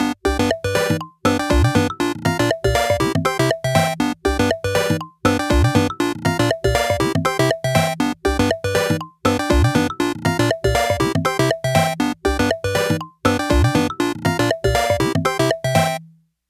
snackattack-ambience.mp3